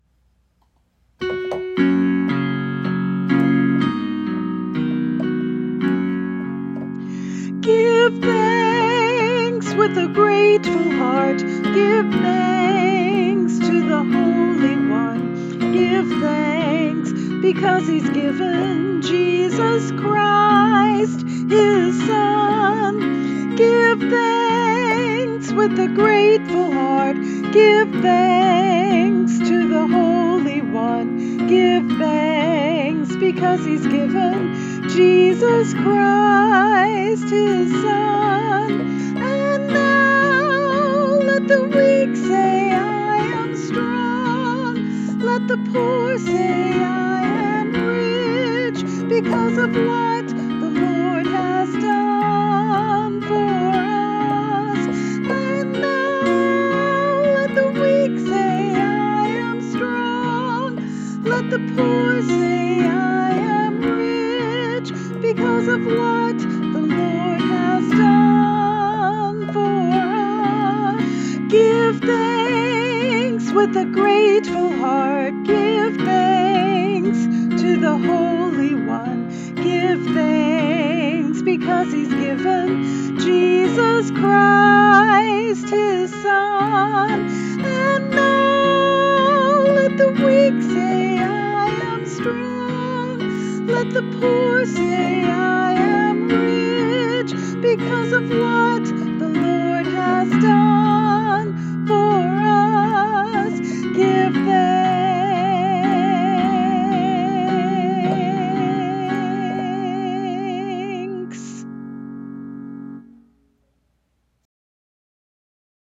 Vocalist
Keyboard